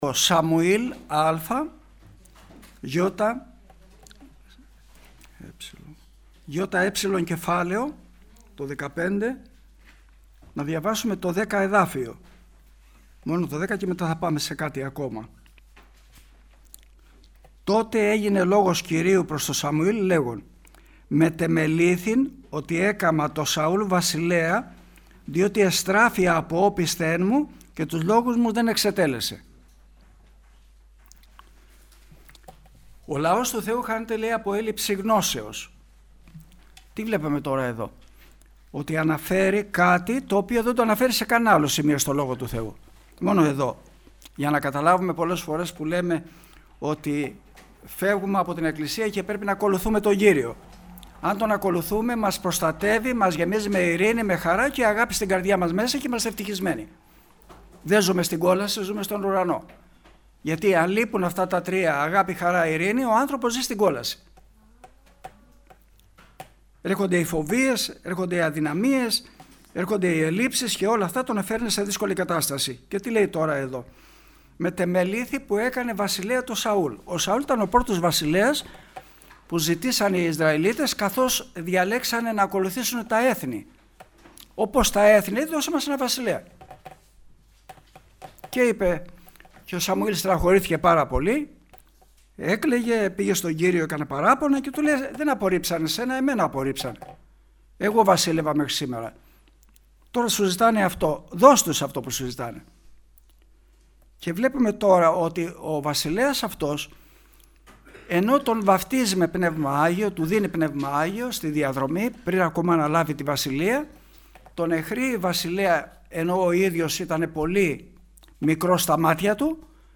Κηρυγμα Ευαγγελιου